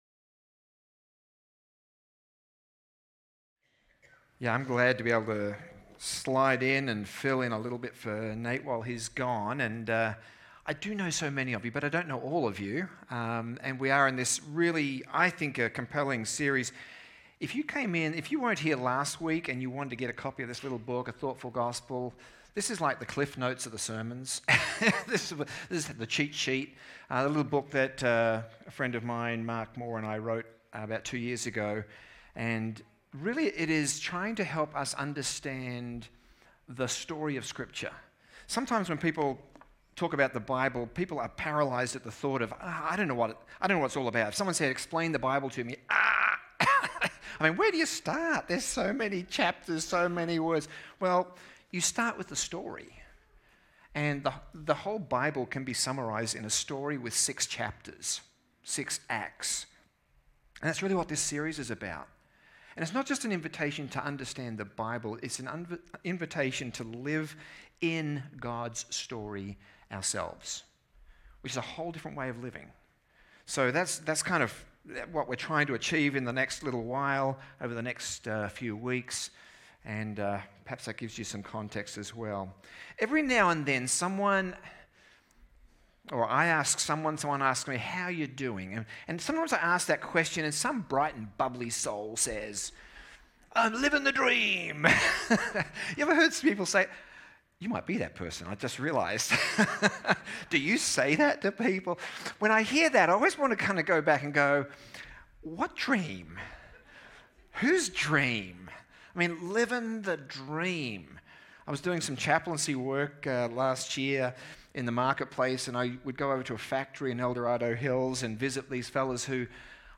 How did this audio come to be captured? This is a partial recording.